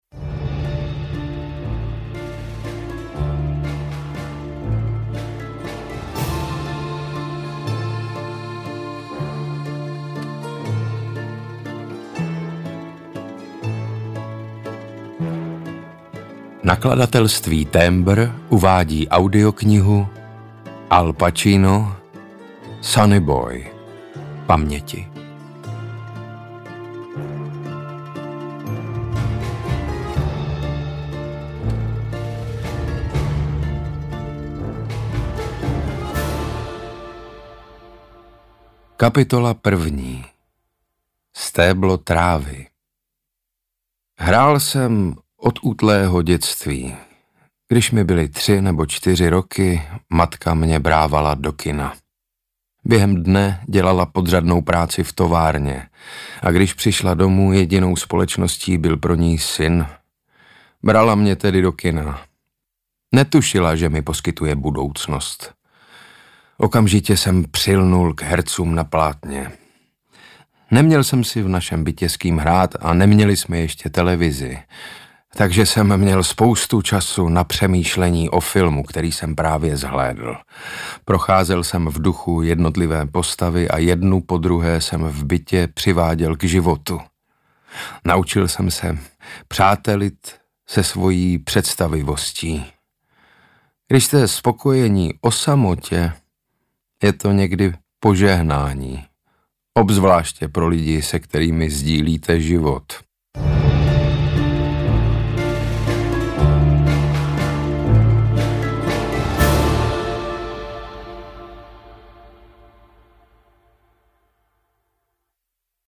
Ukázka z knihy
• InterpretSaša Rašilov